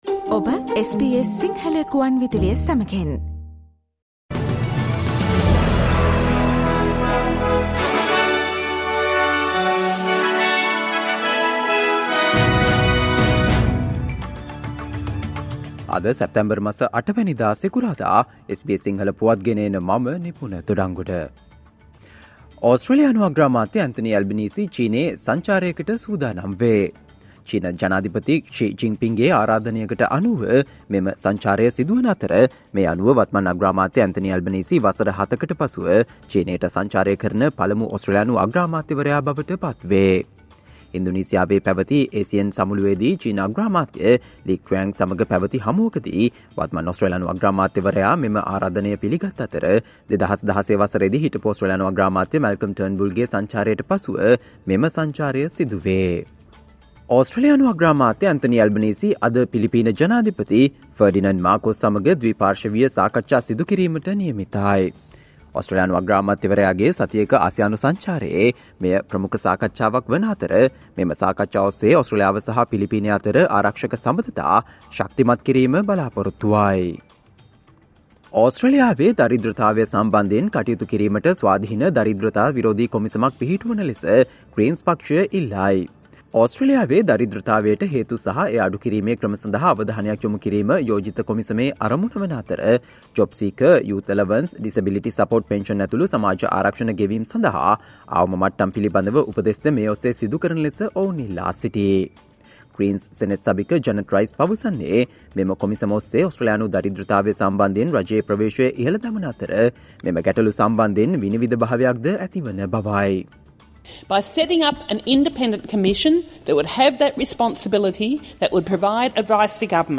Australia news in Sinhala, foreign and sports news in brief - listen, today - Friday 09 September 2023 SBS Radio News